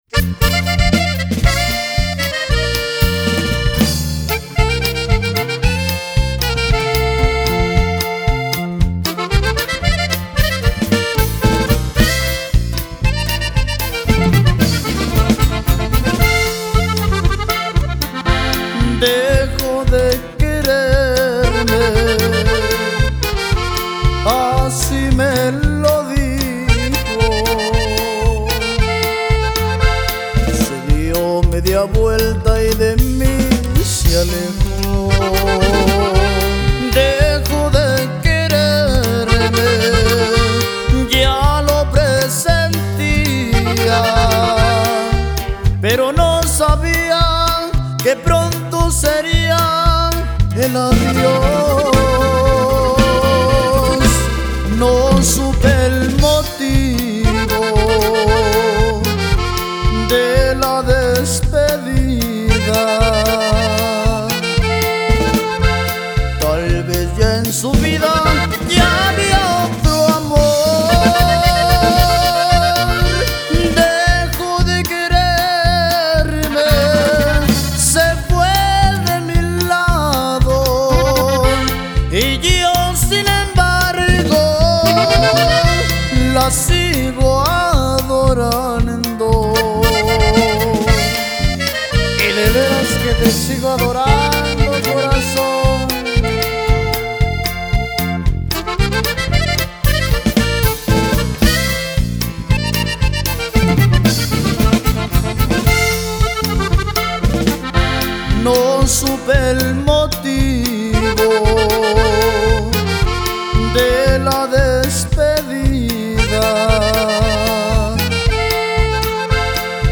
música Regional Mexicana